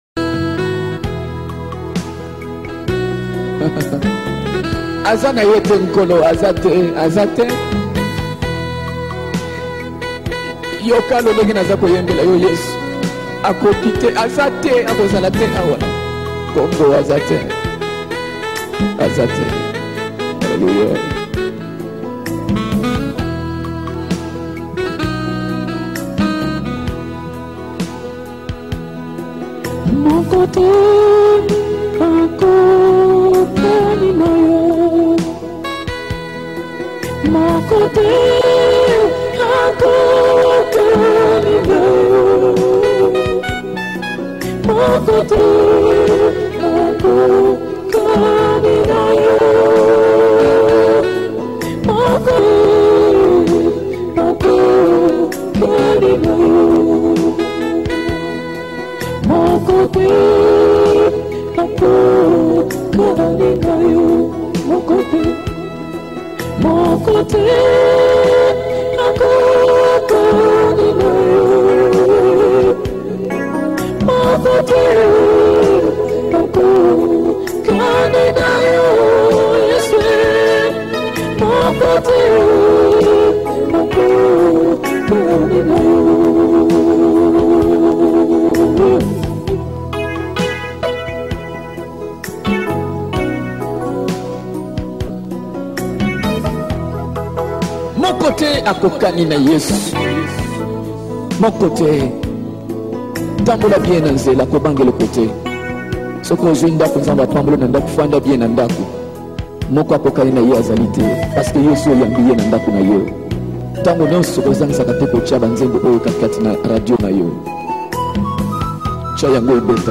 Gospel 2002